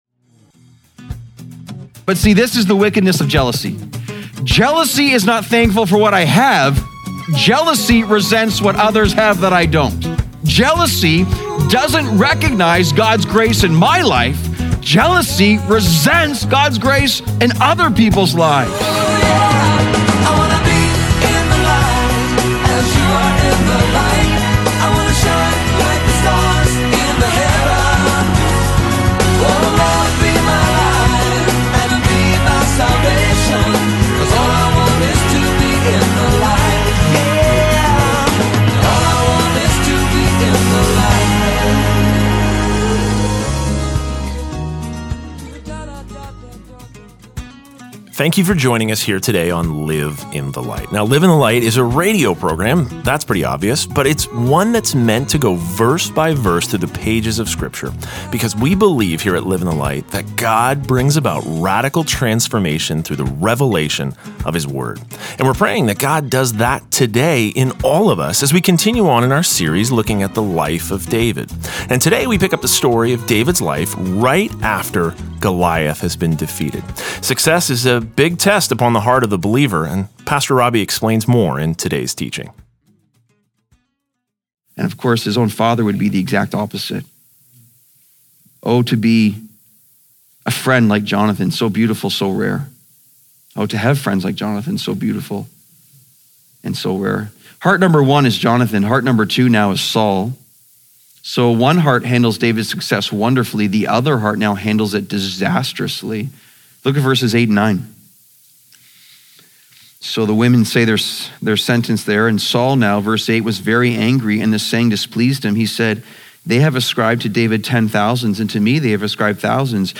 Daily Broadcast